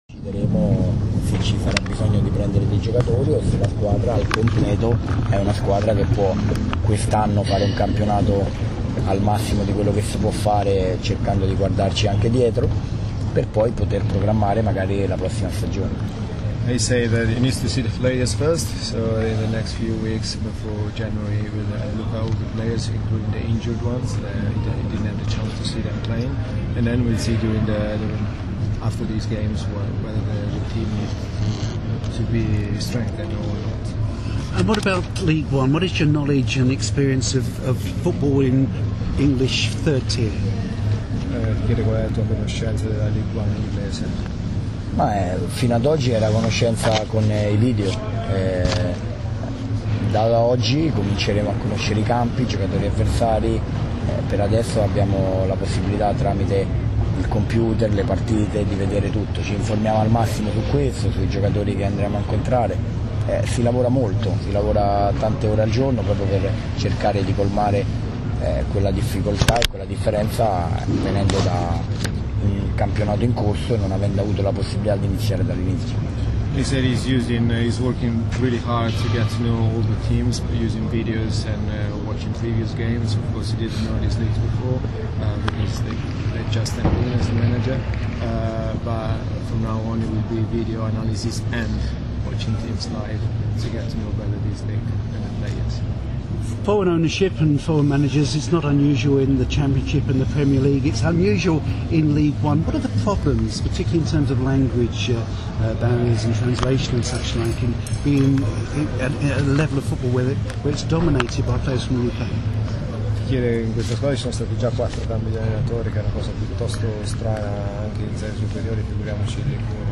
New Manager Fabio Liverani, speaking after Orient vs Peterborough